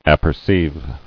[ap·per·ceive]